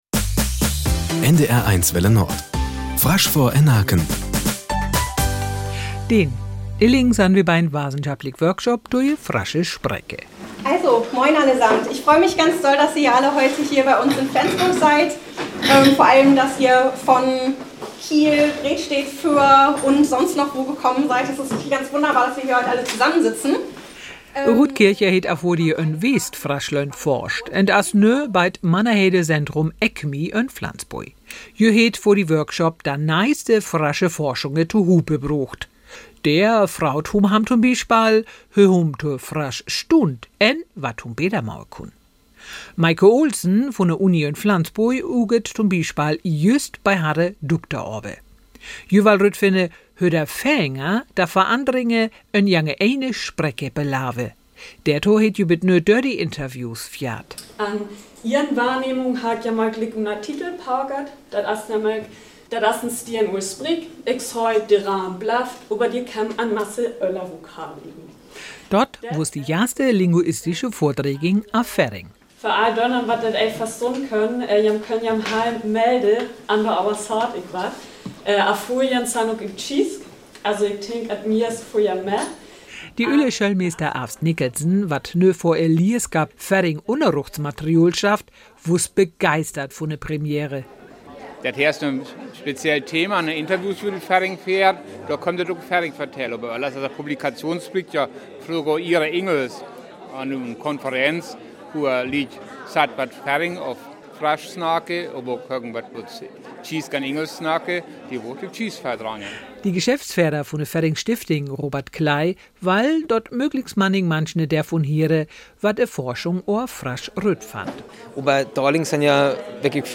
Nachrichten 10:00 Uhr - 10.10.2024